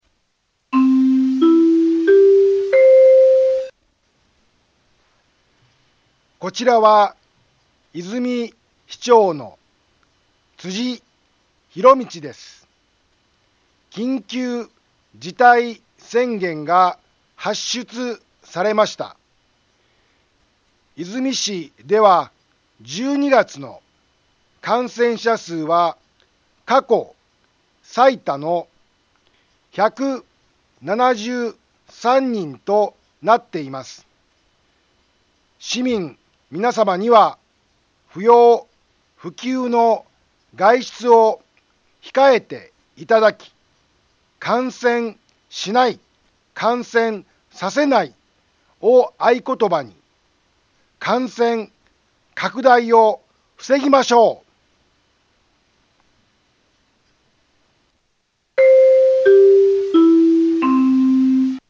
BO-SAI navi Back Home 災害情報 音声放送 再生 災害情報 カテゴリ：通常放送 住所：大阪府和泉市府中町２丁目７−５ インフォメーション：こちらは、和泉市長の辻 ひろみちです。 緊急事態宣言が発出されました。 和泉市では１２月の感染者数は過去最多の１７３人となっています。